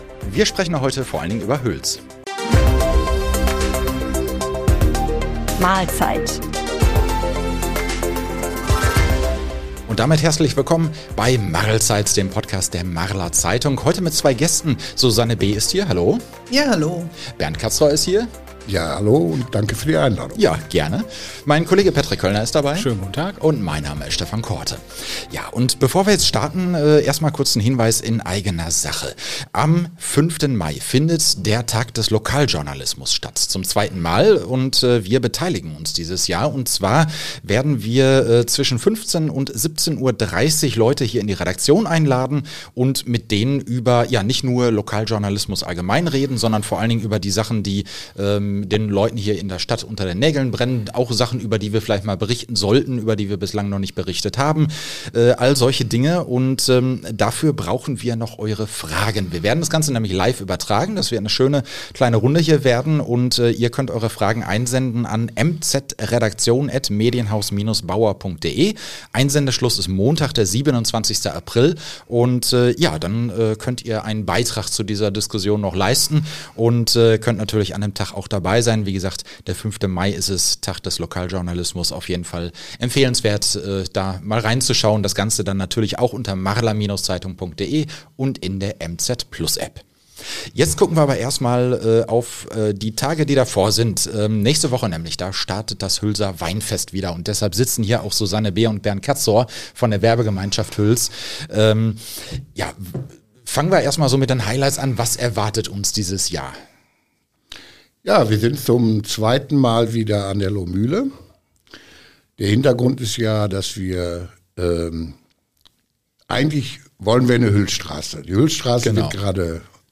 im Studio.